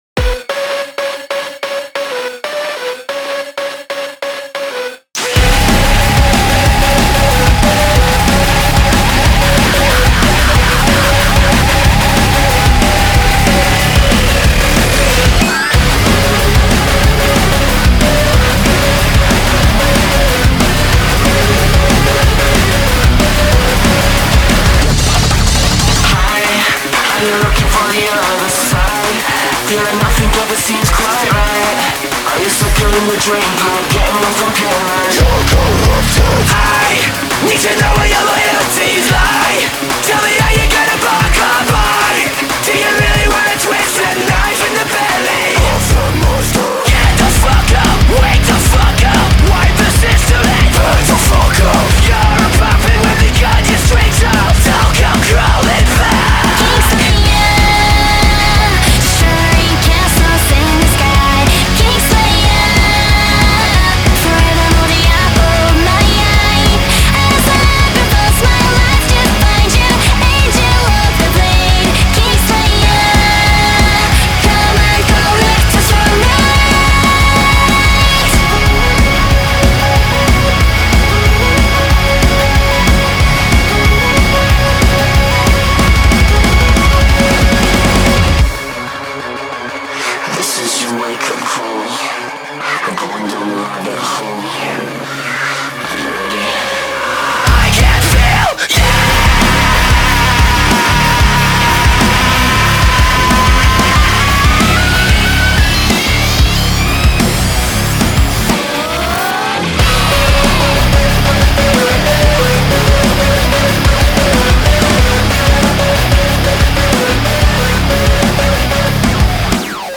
BPM46-185
Audio QualityMusic Cut